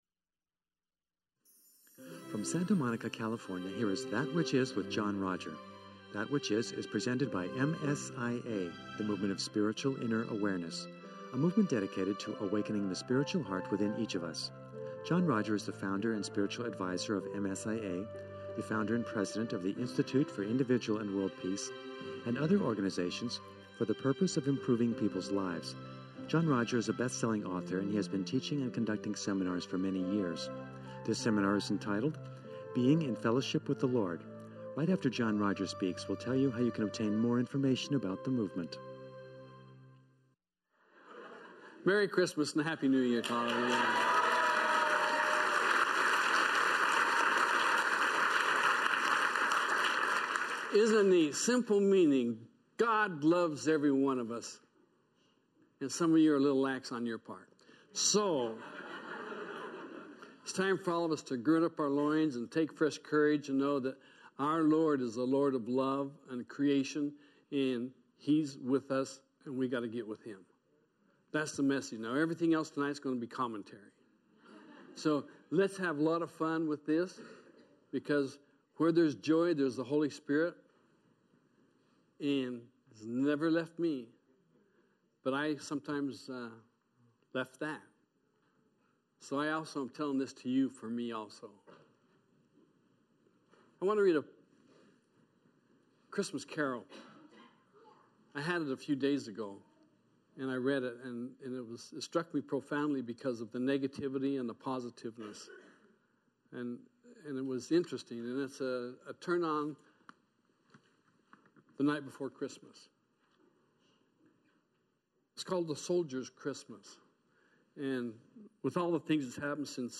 Here is a seminar for the current day of uncertainty and fear.